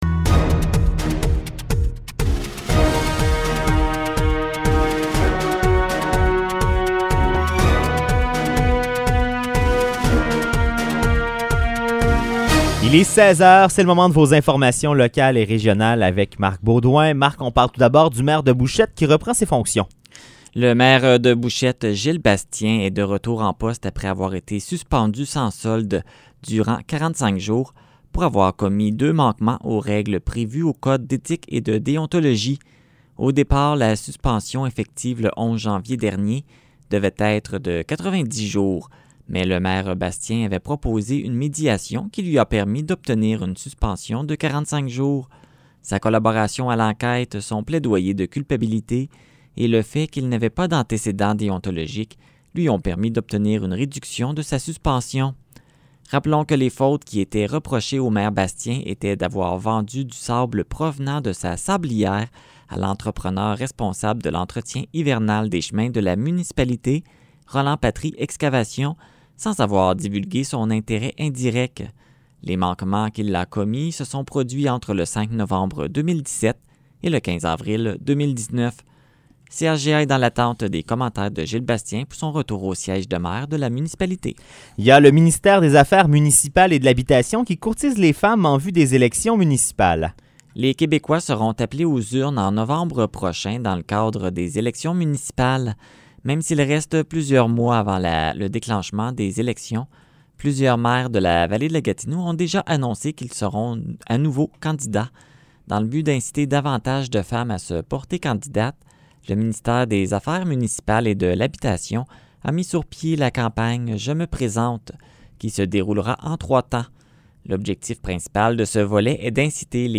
Nouvelles locales - 1er mars 2021 - 16 h